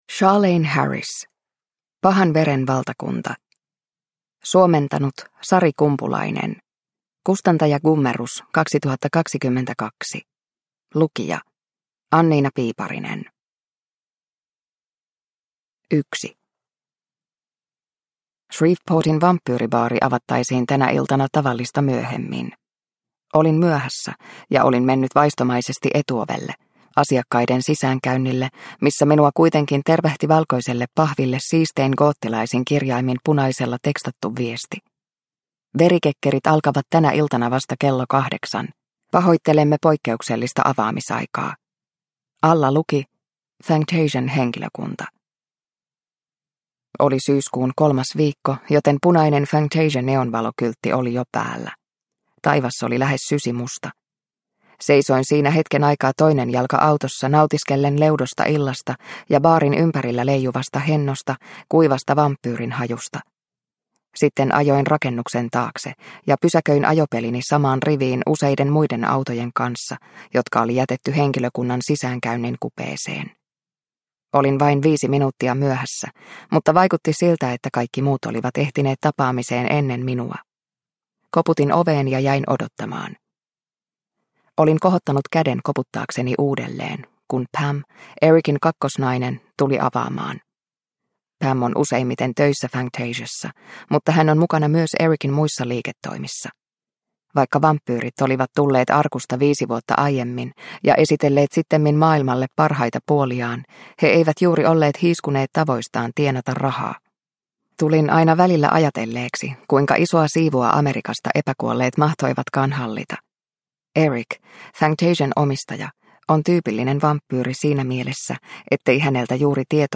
Pahan veren valtakunta – Ljudbok – Laddas ner